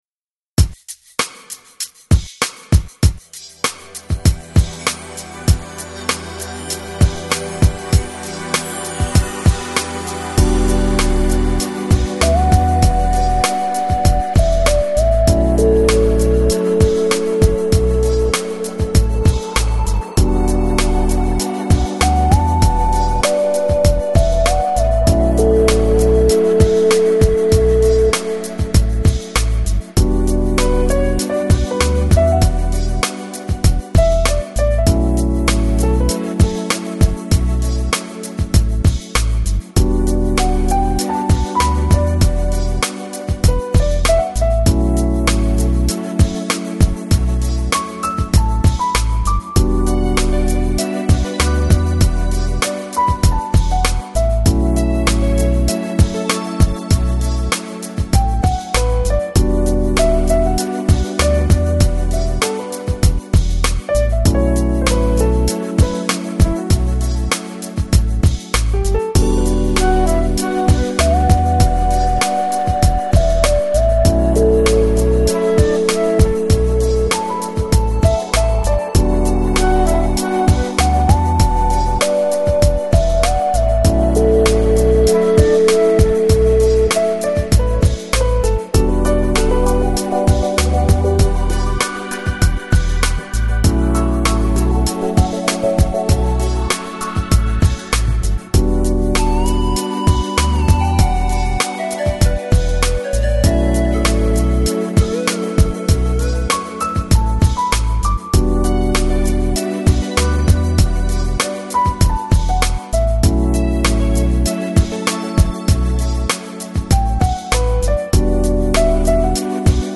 FLAC Жанр: Smooth Jazz Издание